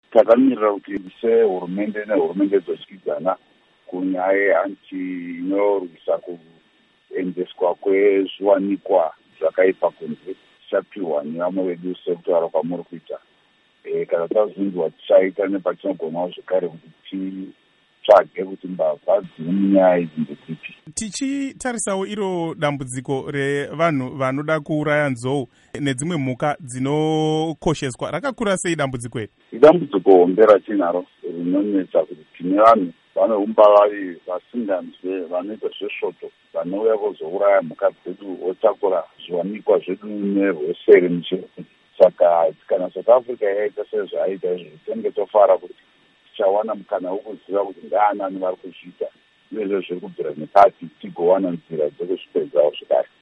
Hurukuro naVaSaviour Kasukuwere